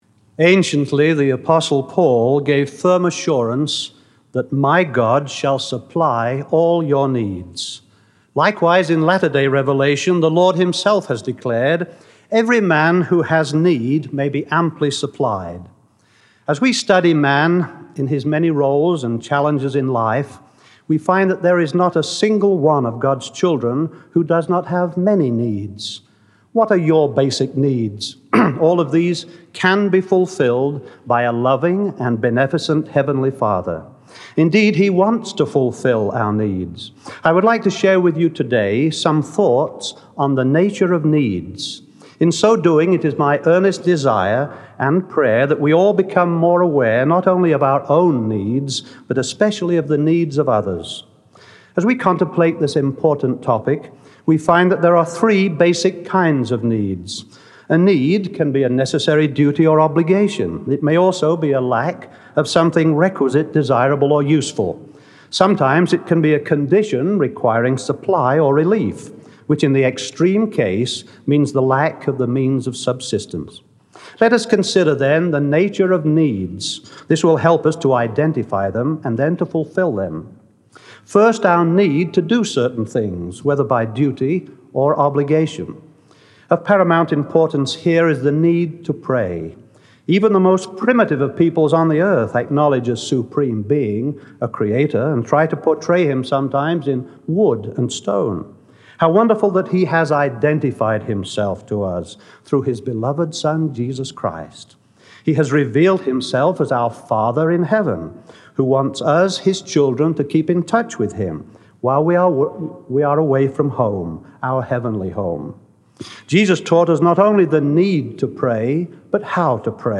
Audio recording of The Nature of Needs by Derek A. Cuthbert